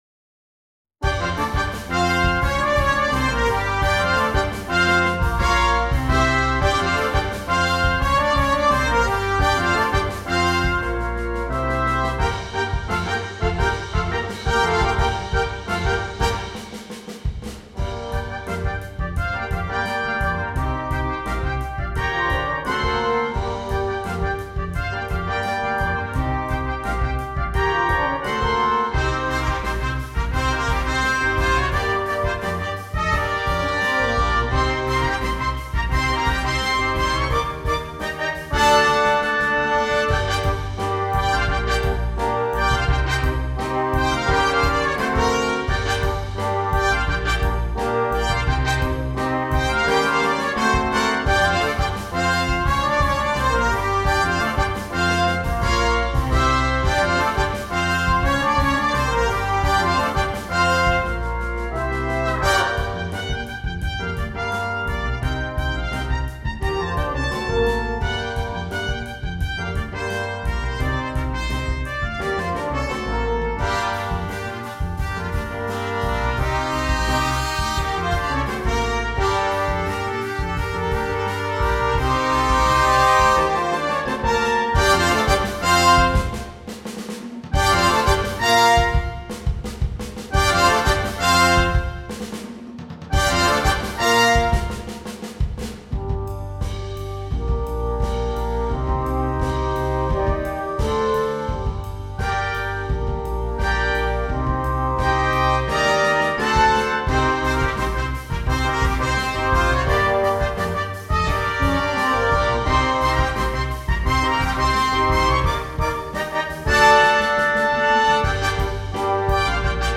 Brass
8 Trumpets and Optional Rhythm Section